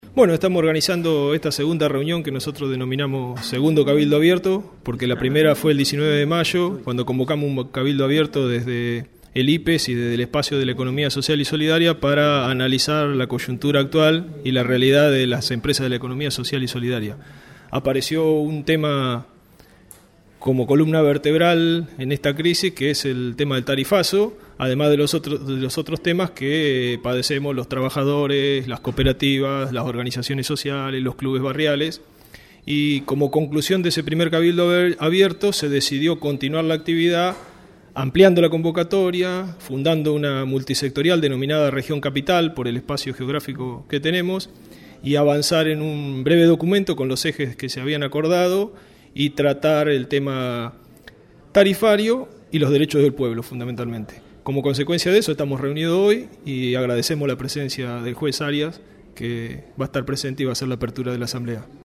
momentos antes de comenzar la actividad